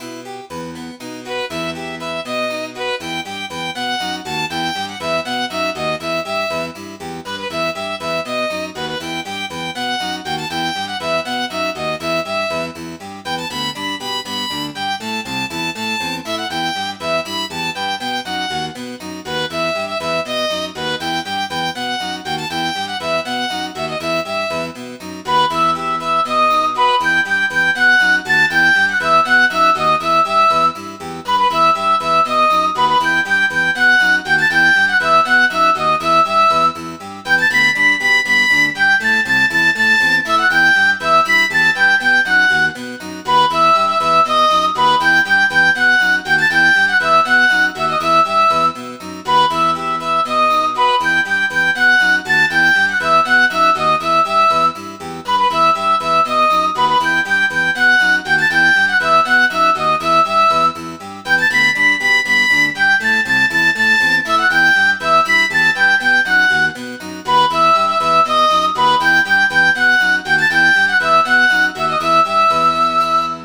Midi File, Lyrics and Information to Young Ladies in Town